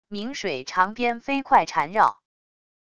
冥水长鞭飞快缠绕wav音频